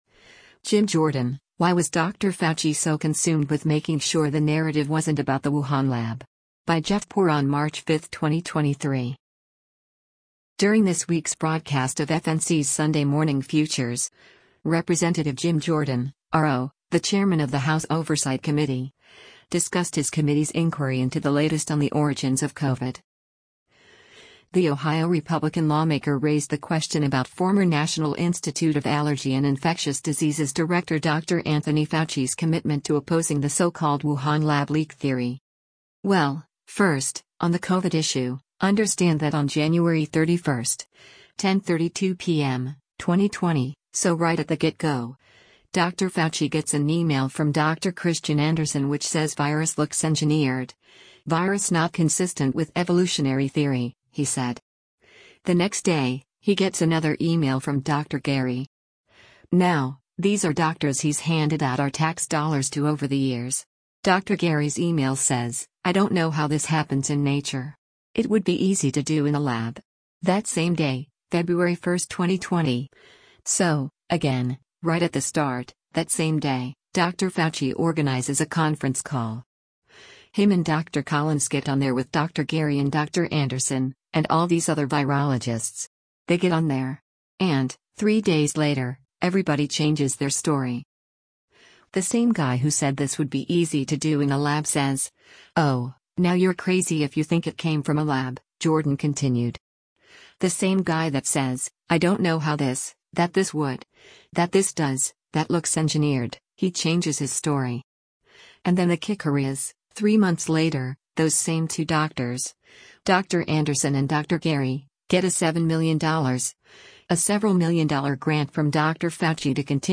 During this week’s broadcast of FNC’s “Sunday Morning Futures,” Rep. Jim Jordan (R-OH), the chairman of the House Oversight Committee, discussed his committee’s inquiry into the latest on the origins of COVID.